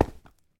minecraft / sounds / step / stone6.ogg
stone6.ogg